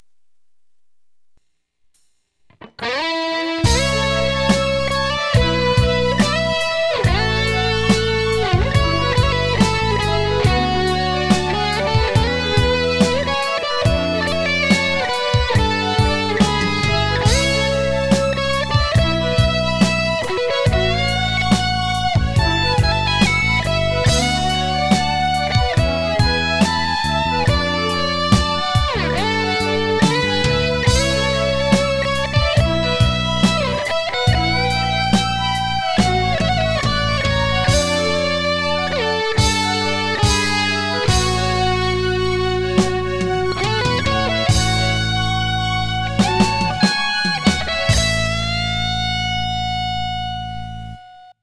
現在、REC環境が最悪のため、手直しなどはしておりません。(^-^;A